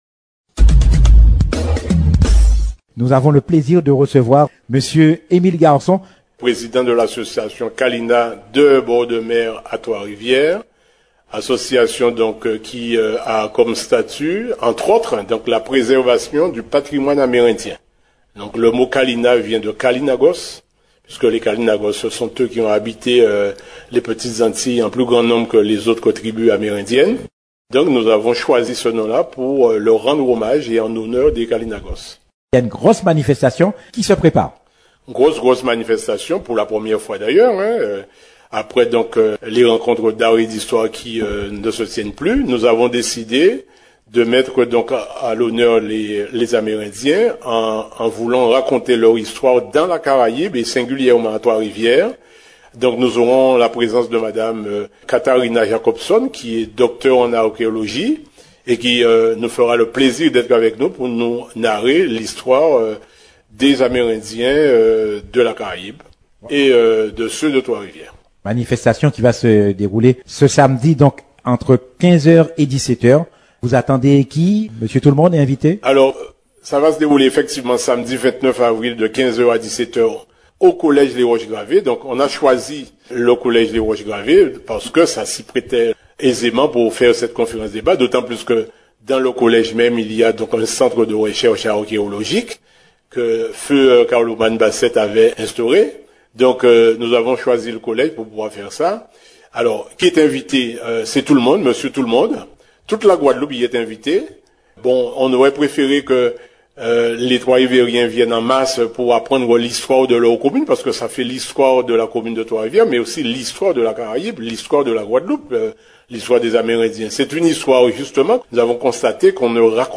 Samedi 29 avril 2023, 15h00-17h00 : Conférence débat sur le thème « Histoire des Amérindiens dans la Caraïbe et plus singulièrement Trois-Rivières.